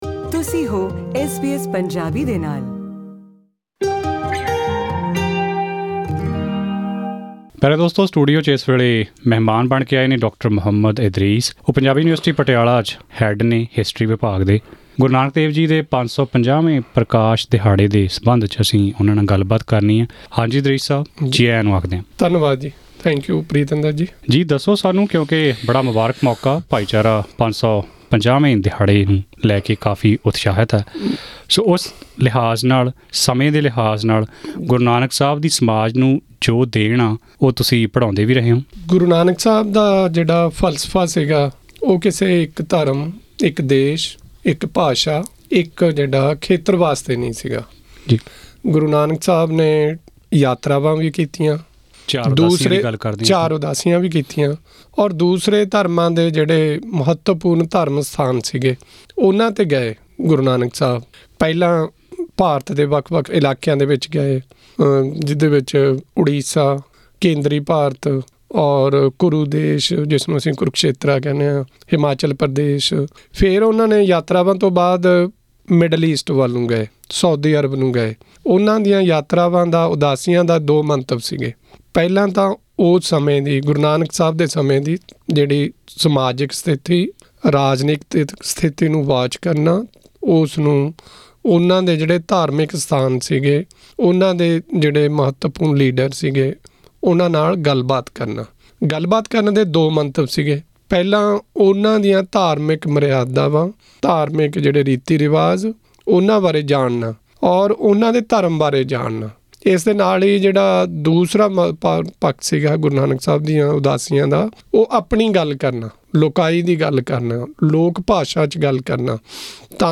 ਇੱਕ ਵਿਸ਼ੇਸ਼ ਗੱਲਬਾਤ।
ਆਪਣੇ ਆਸਟ੍ਰੇਲੀਅਨ ਦੌਰੇ ਦੌਰਾਨ ਐਸ ਬੀ ਐਸ ਮੈਲਬੌਰਨ ਸਟੂਡੀਓ ਵਿਖੇ।